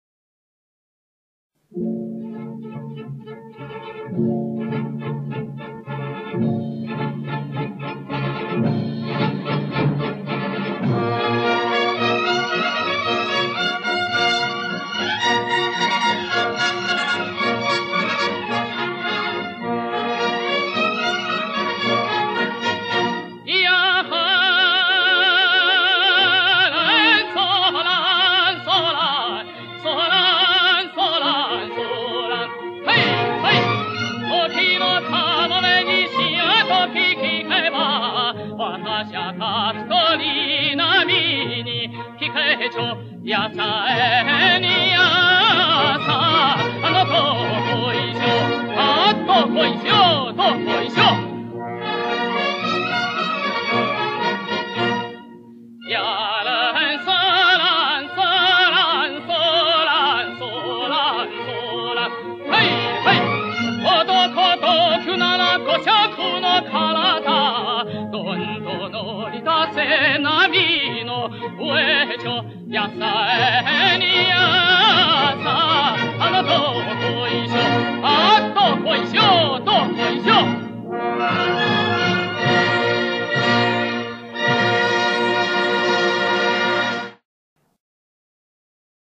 [21/11/2011]歌唱大师施鸿鄂演唱的日本歌曲【拉网小调】，选自施鸿鄂1979独唱音乐会资料